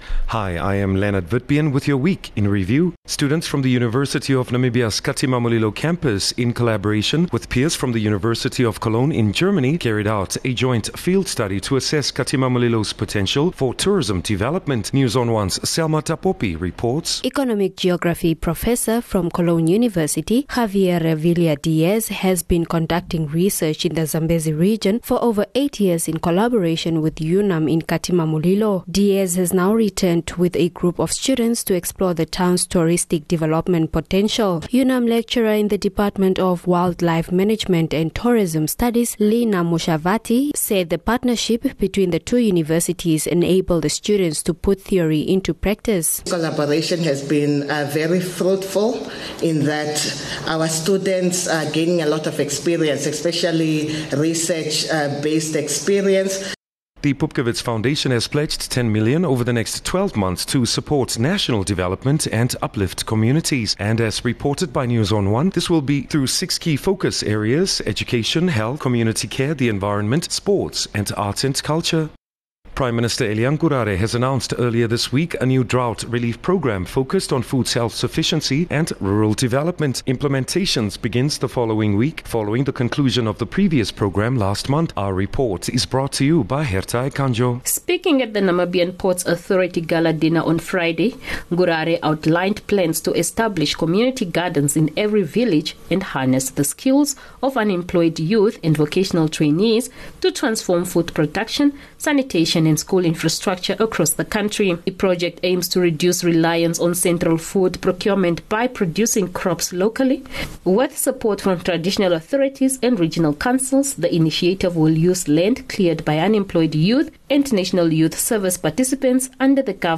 Future Media News Bulletins